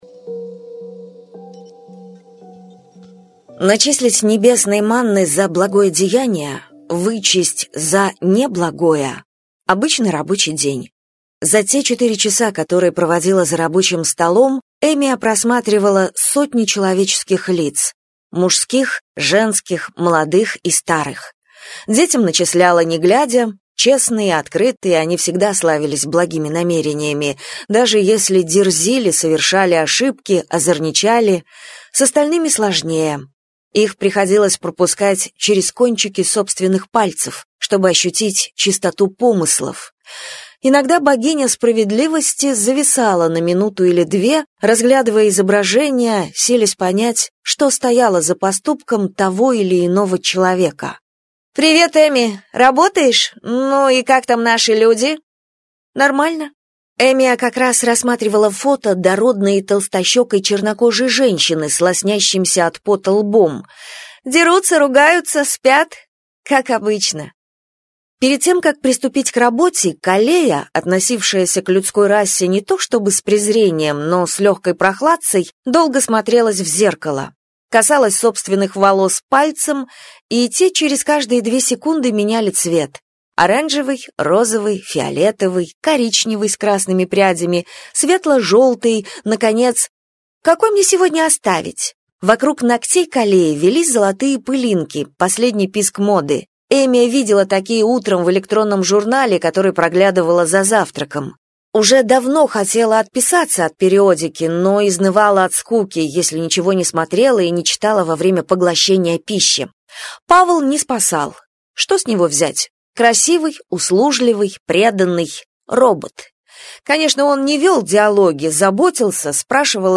Аудиокнига Пообещай - купить, скачать и слушать онлайн | КнигоПоиск